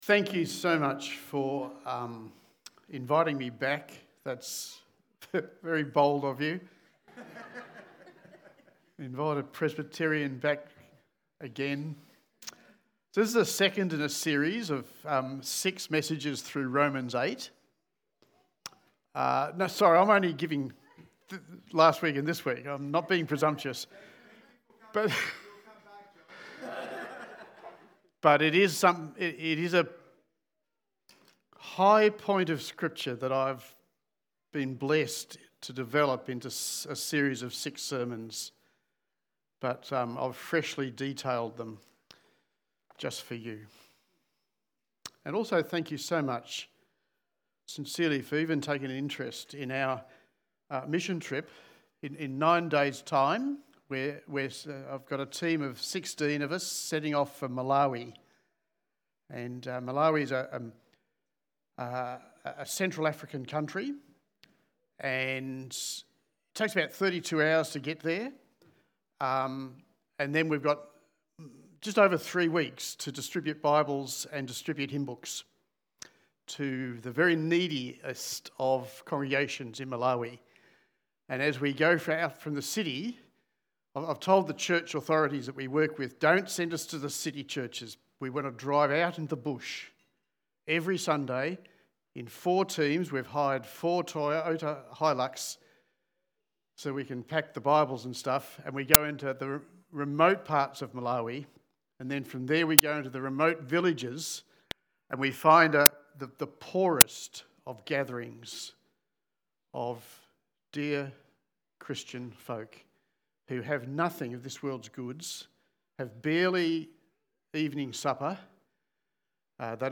Church-Sermon-150625.mp3